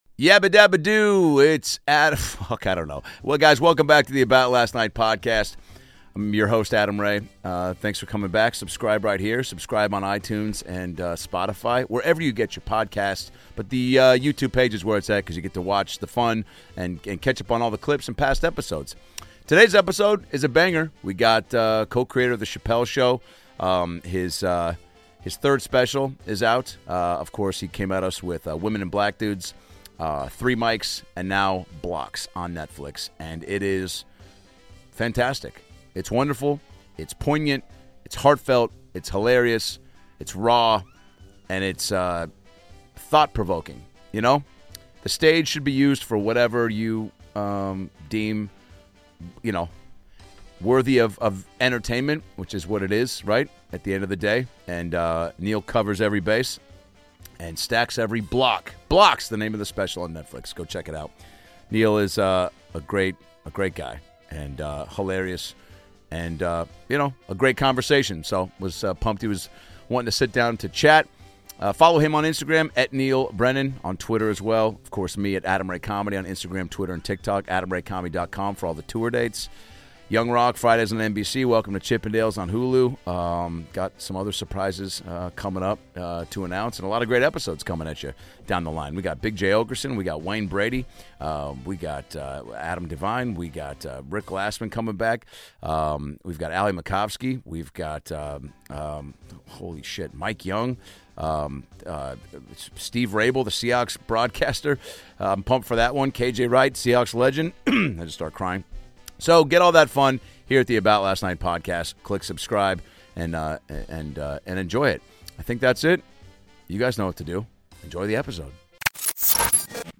Neal Brennan joins the About Last Night Podcast with Adam Ray to talk about his new special "Blocks" om Netflix and more! Neal Brennan is a comedian, writer, director, and producer known for his work on hit shows like Chappelle's Show, The Daily Show, and Inside Amy Schumer.